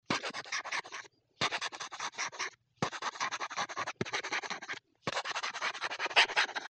Звуки писания пером
Шум пера при письме